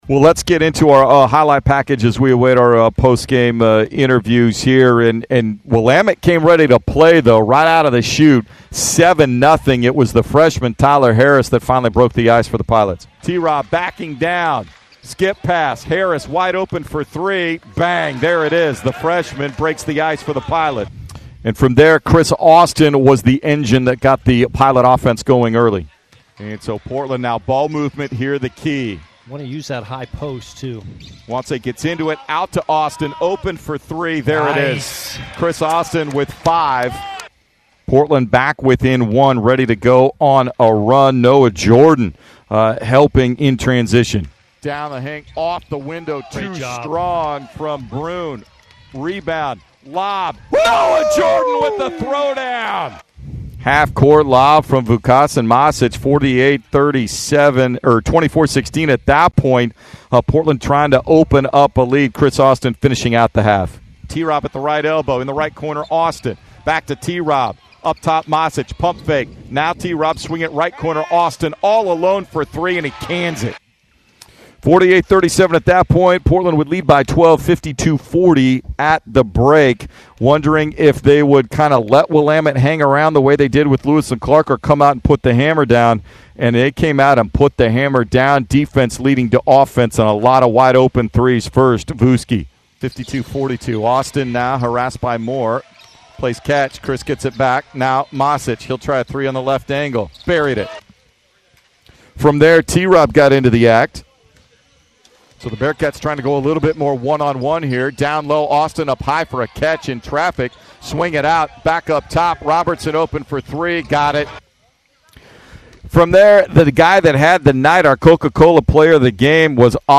Willamette Radio Highlights
November 25, 2023 Radio highlights from Portland's 107-84 win against Willamette on Nov. 25, 2023 at Chiles Center.